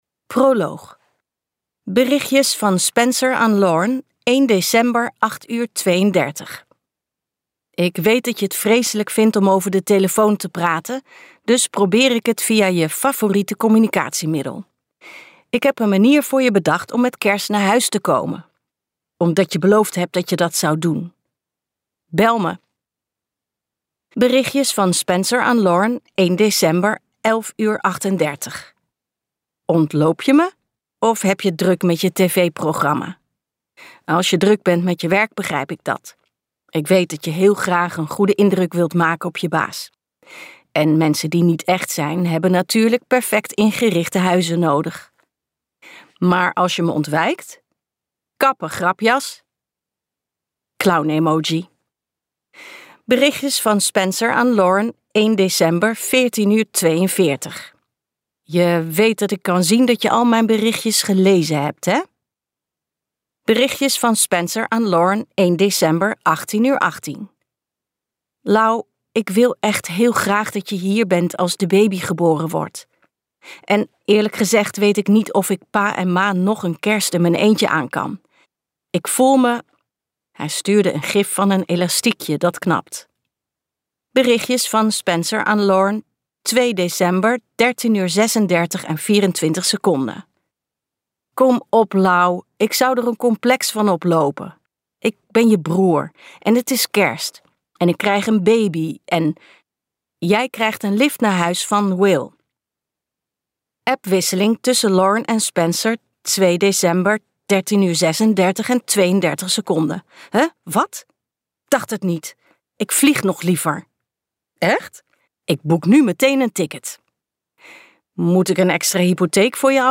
KokBoekencentrum | Opgescheept met jou luisterboek